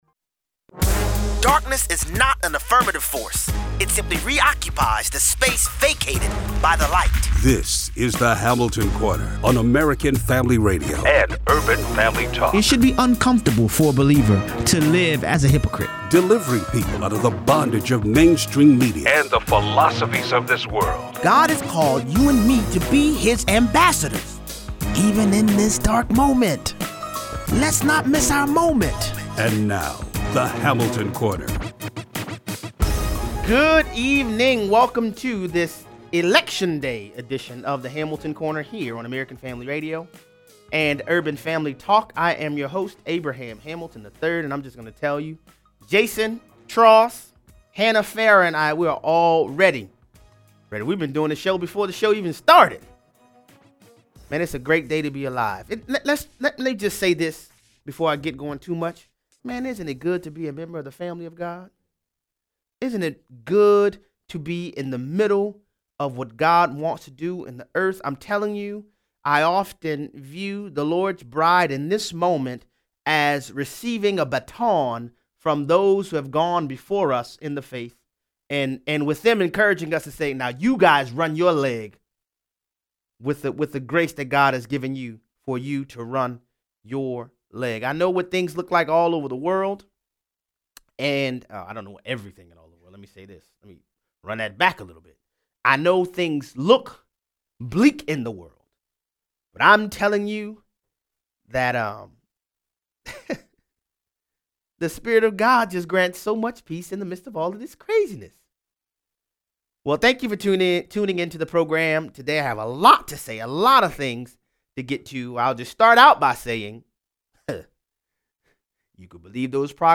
Civic engagement for the Christian is part and parcel to our salt and light duty. 0:23 - 0:40: The audience breaks out spontaneously into “Amazing Grace” during President Trump’s last rally before the midterm elections. 0:43 - 0:60: Reporter leaves a message for Michigan Senate Candidate John James but doesn’t realize the recorder was still on when she says what she really thinks about him. Callers weigh in.